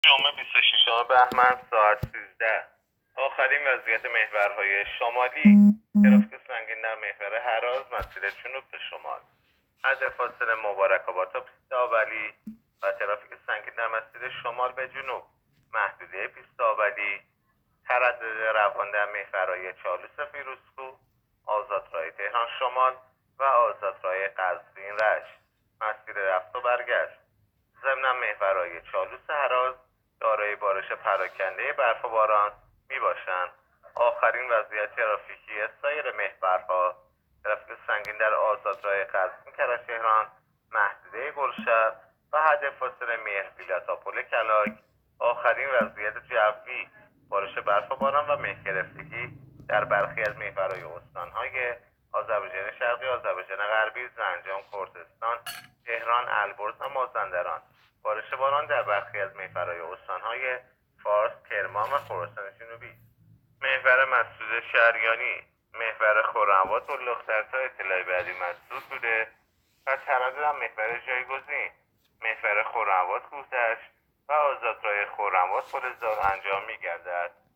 گزارش رادیو اینترنتی از آخرین وضعیت ترافیکی جاده‌ها ساعت ۱۳ بیست و ششم بهمن؛